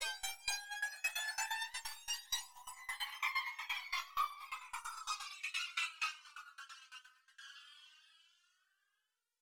Flanged Drumma Rise.wav